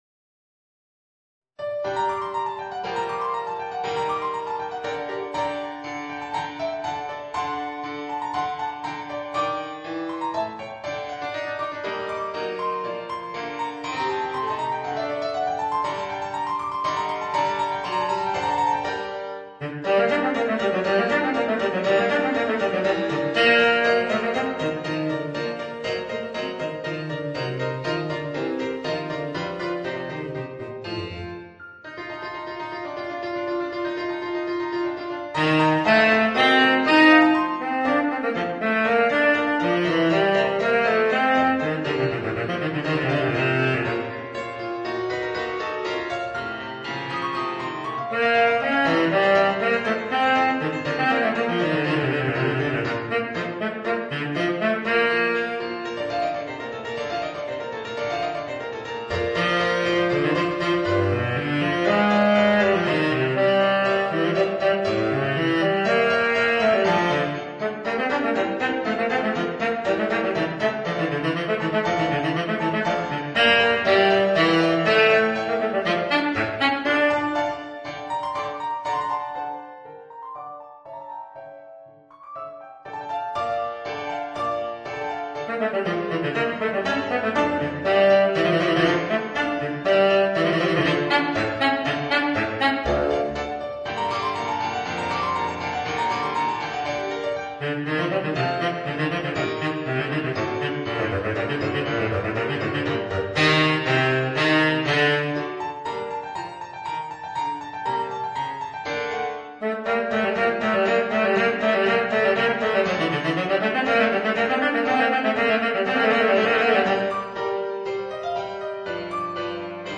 Voicing: Baritone Saxophone and Organ